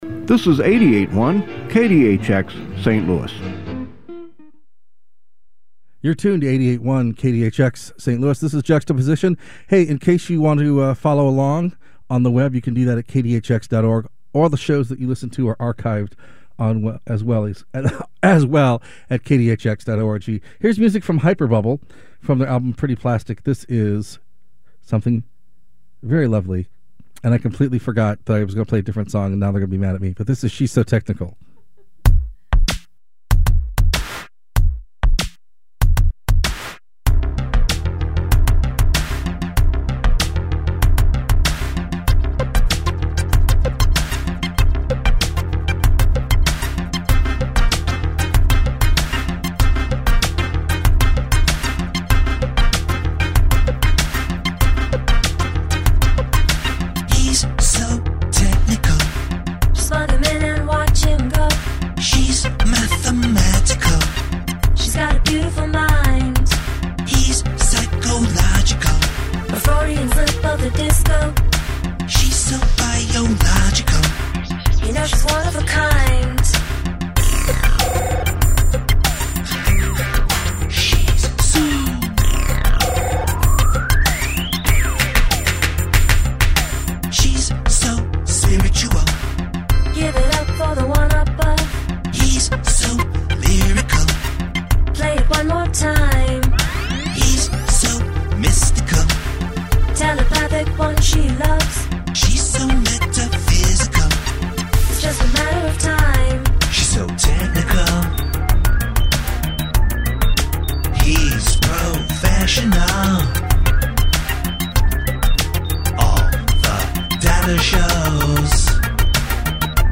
electropop band
live in the studio